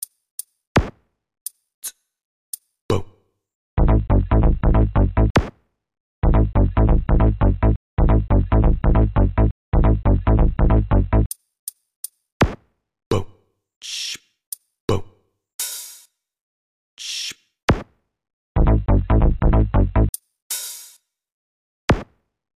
научился на мб музыку писать только в одной линии звучания вот проба звука.